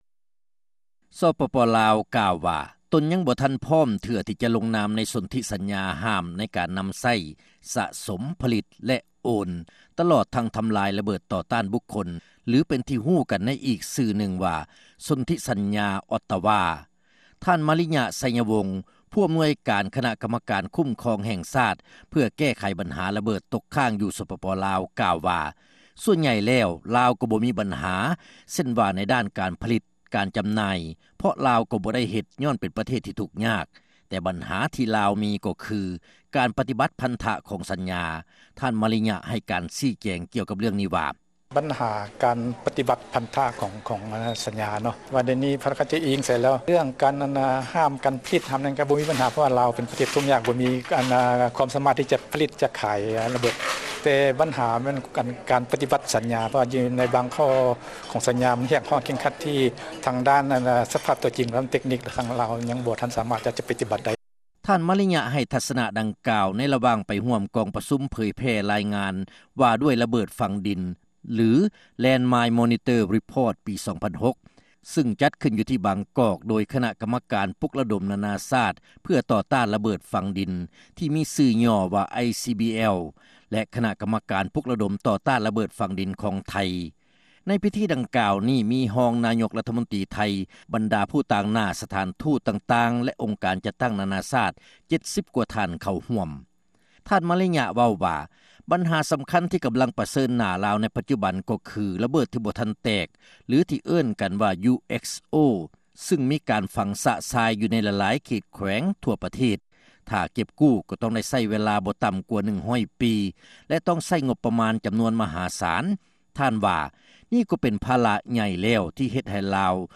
ນາງ JODY WILLIAMS ເອກອັຄຣາຊທູດຂອງອົງການ ICBL ຊຶ່ງເຄີຍໄດ້ຮັບລາງວັນໂນແບລມາແລ້ວກ່າວຊີ້ ແຈງກ່ຽວກັບເຣື່ອງນີ້ວ່າ: